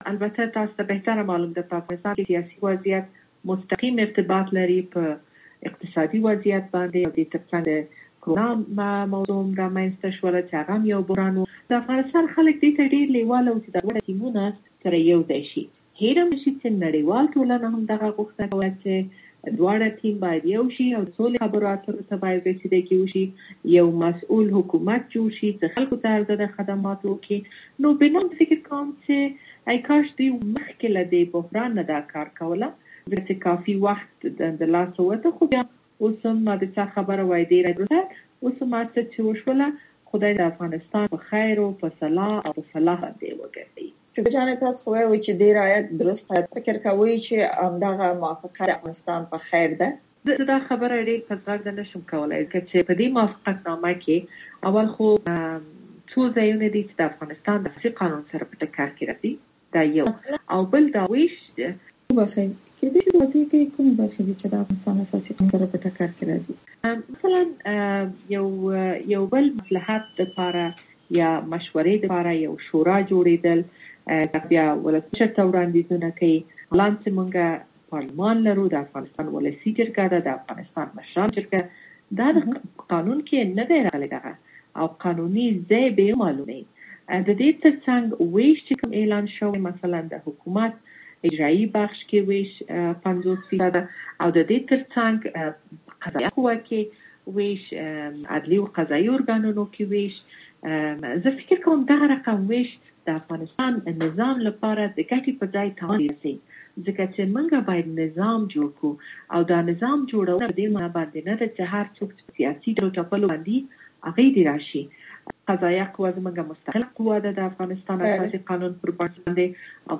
مرکې
امریکا غږ سره د شکریه بارکزۍ مرکه